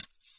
敲击声 " 刀唰唰2
描述：打击乐的刀子
标签： 敲击 敲击 随机的 声音
声道立体声